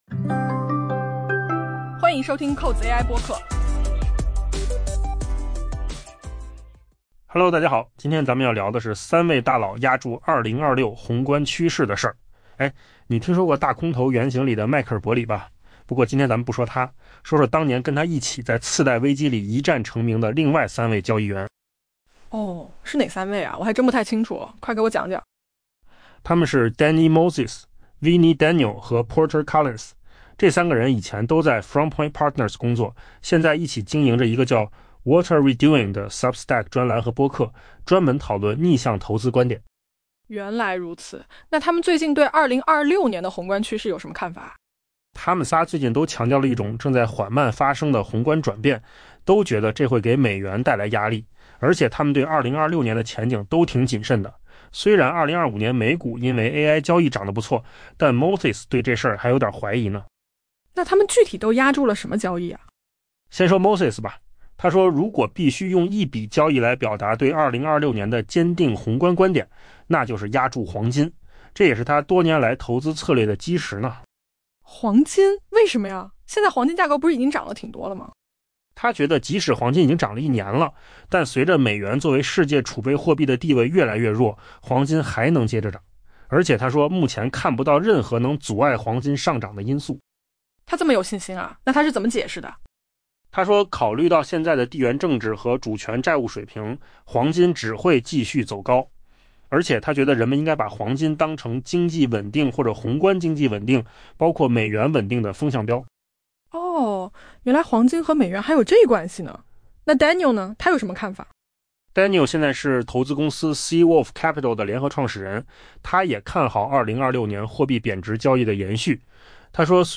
AI 播客：换个方式听新闻 下载 mp3 音频由扣子空间生成 尽管 《大空头》 原形中，迈克尔·伯里如今备受关注，但当年还有几位交易员也同样 「不容小觑」。